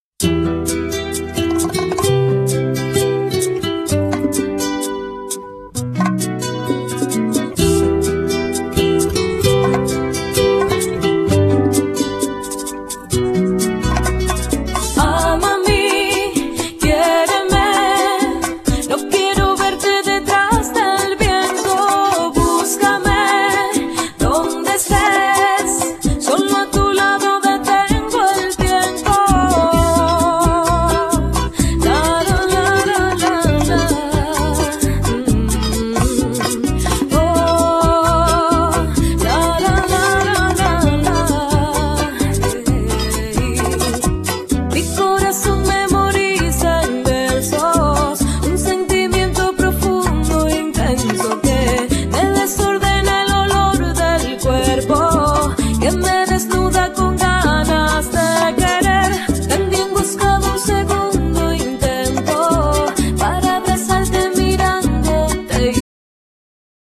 Genere : Pop Latin